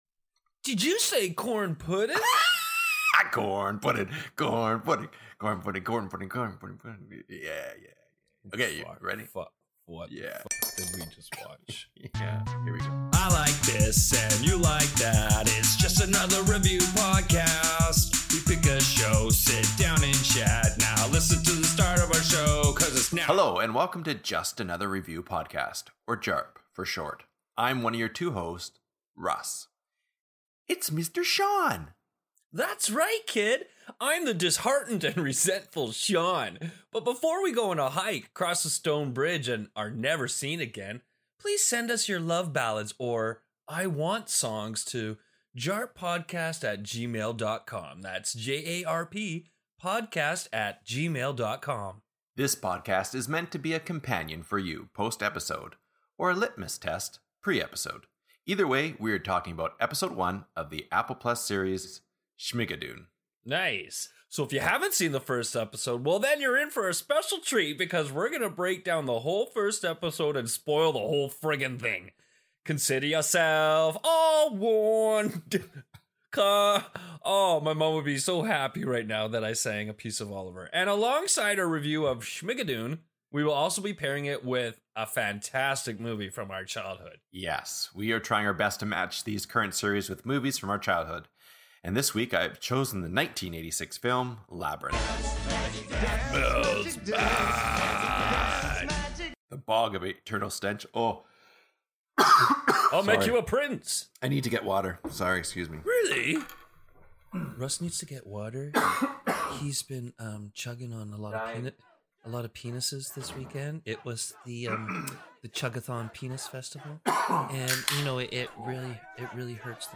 The guys stumble their way through Colour-Blind Casting, the pronunciation of "Cecily", and a series of coughing fits & seasonal allergies.